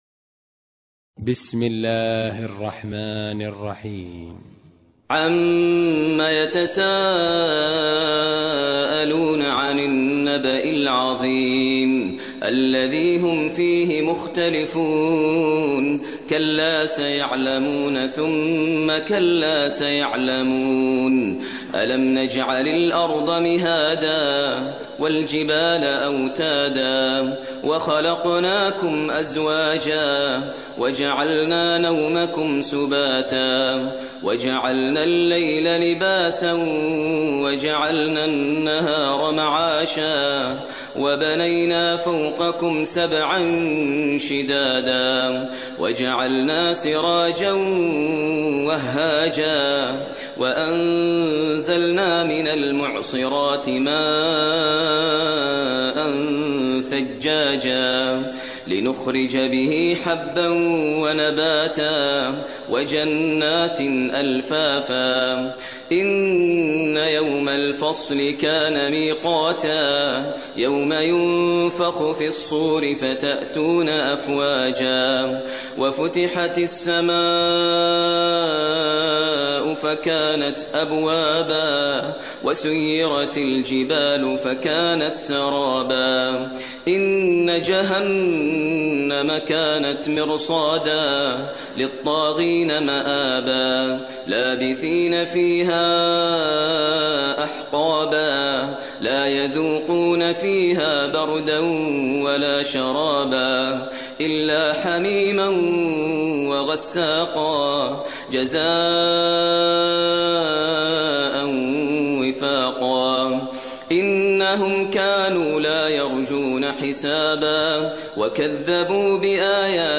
ترتیل سوره نبا با صدای ماهر المعیقلی
078-Maher-Al-Muaiqly-Surah-An-Naba.mp3